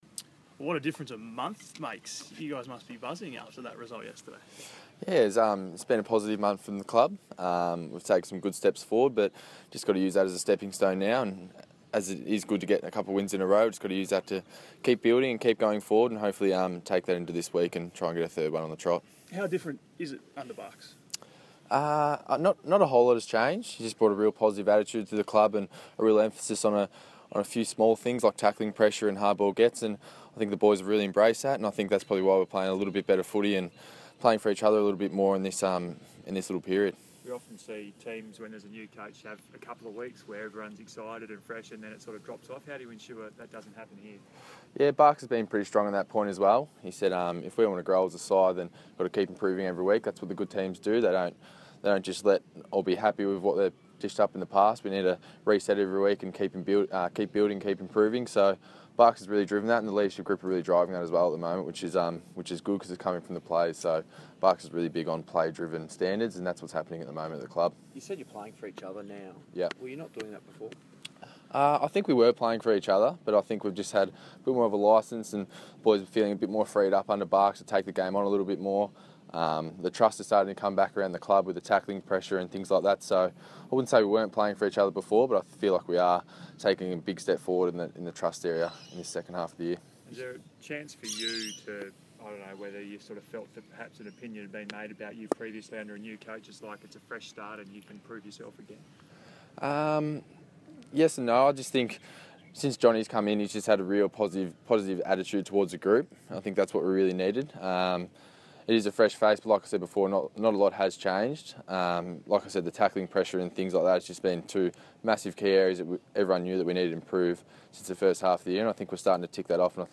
press conference